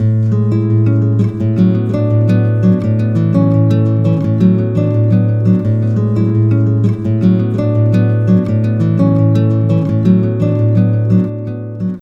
To make this sound more like a realistic echo, we can change the volume of the delayed audio by scaling the amplitude on the delay (copy) file.